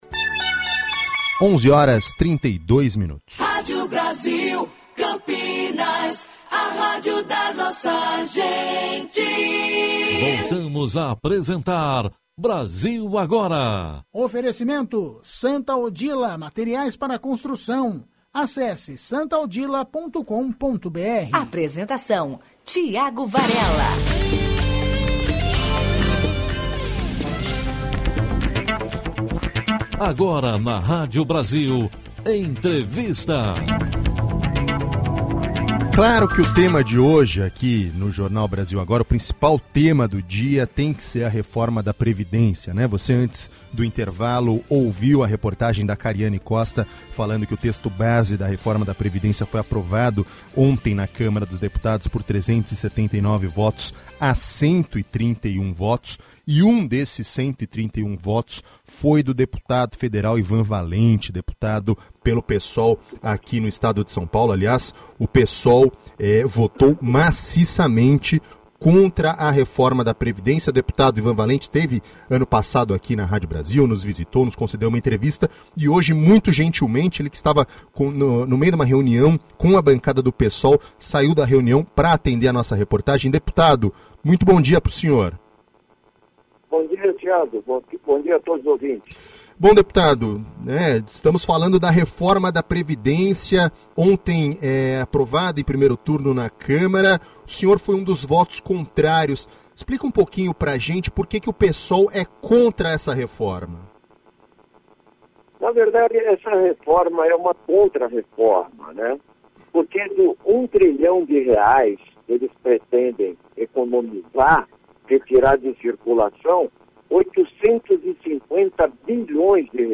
Entrevista do deputado Ivan Valente para a Rádio Brasil Campinas avaliando a votação da PEC 06 (Previdência), a ação do Psol e os próximos passos.